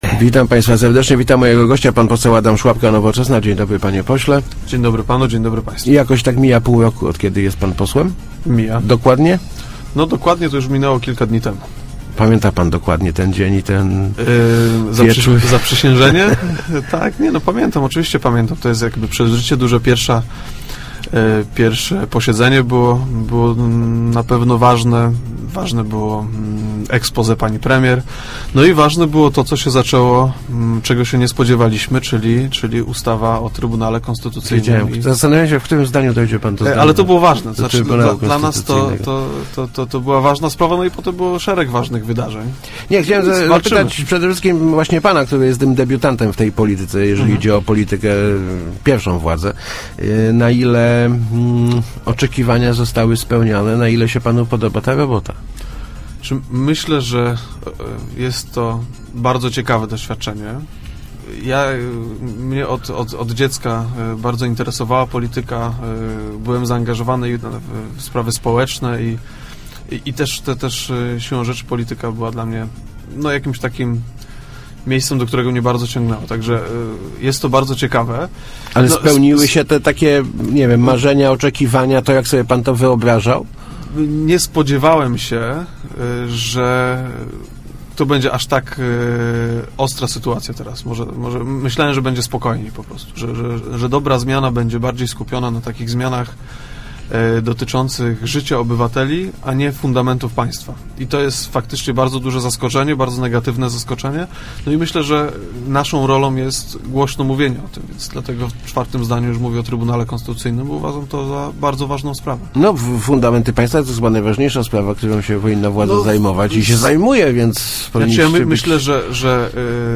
Projekt 500+ obliczony jest na "polityk� sonda�ow�" PiS - mówi� w Rozmowach Elki pose� Adam Sz�apka z Nowoczesnej. Jego zdaniem najwi�kszym problemem programu b�dzie znalezienie pieni�dzy w kolejnych latach.